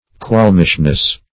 -- Qualm"ish*ness , n. [1913 Webster]